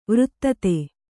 ♪ vřttate